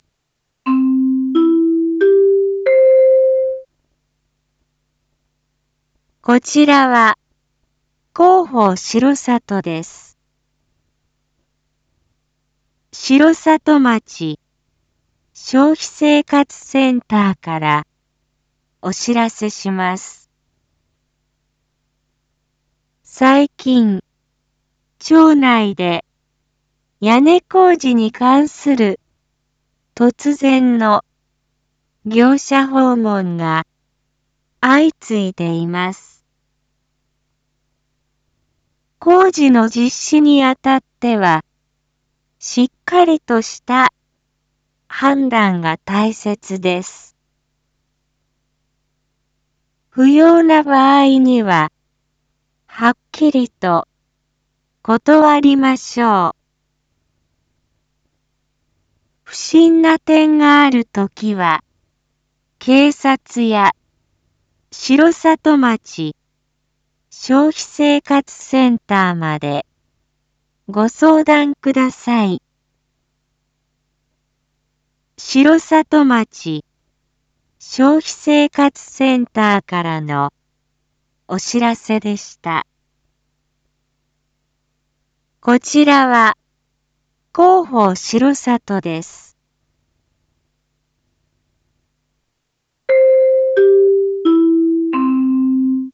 一般放送情報
Back Home 一般放送情報 音声放送 再生 一般放送情報 登録日時：2024-09-03 19:01:37 タイトル：消費生活センターからの注意喚起② インフォメーション：こちらは、広報しろさとです。